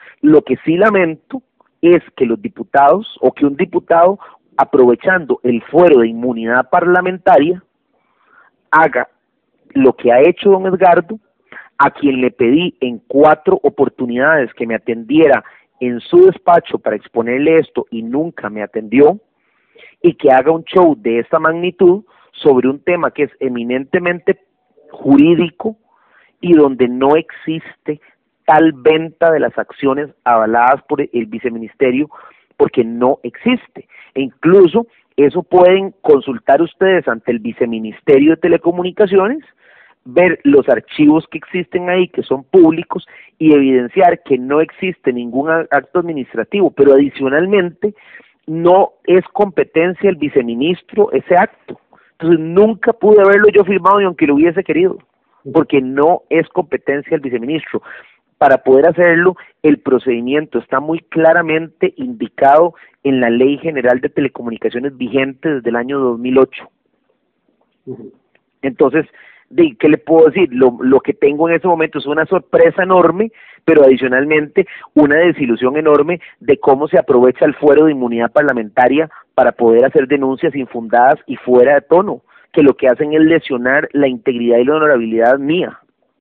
Vía telefónica y con una voz que claramente expresaba su malestar por las declaraciones del legislador, Emilio Arias manifestó a EL MUNDO que sus actuaciones, cuando estuvo al frente del viceministerio de Telecomunicaciones, estuvieron siempre “apegadas al principio de legalidad y a lo que establece la Ley de Telecomunicaciones vigente en el país desde el año 2008”.